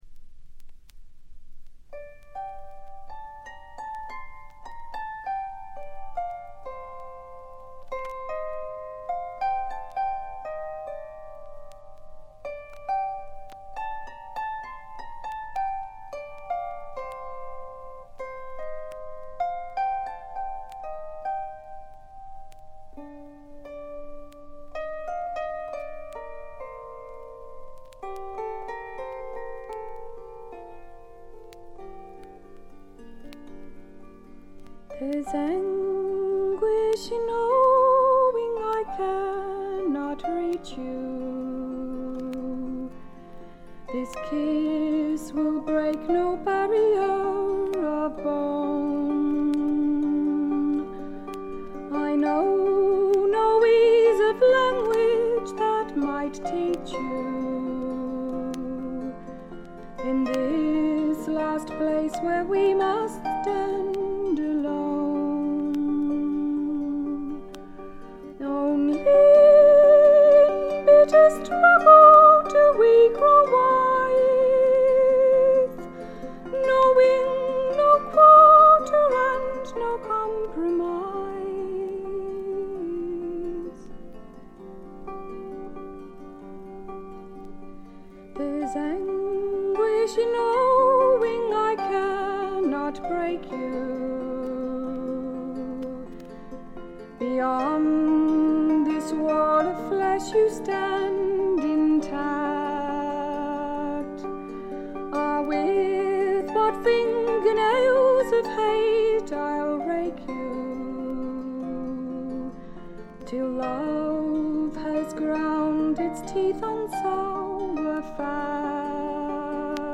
トラディショナル・ソングとトラッド風味の自作曲を、この上なく美しく演奏しています。
試聴曲は現品からの取り込み音源です。
vocal, harp, banjo
viola.